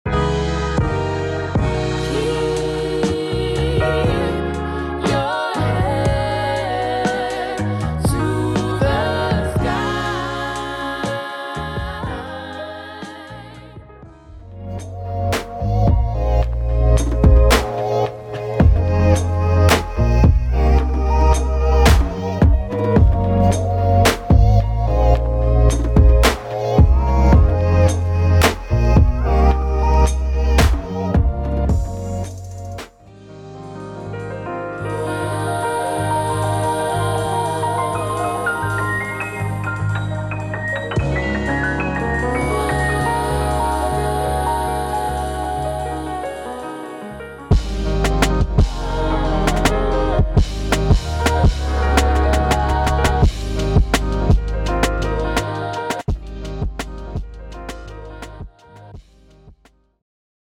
Gospel
soulful essence of traditional and contemporary gospel music